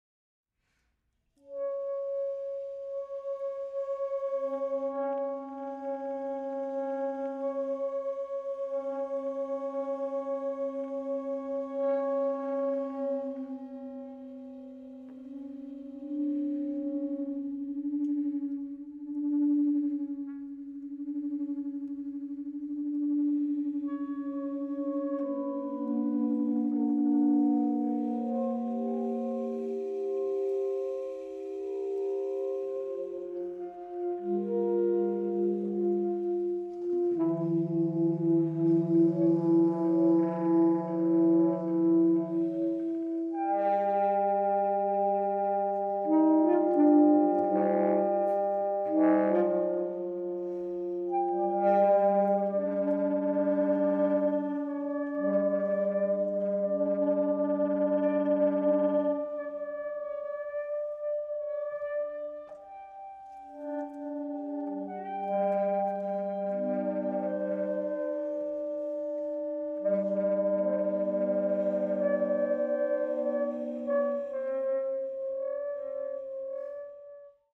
baritone saxophone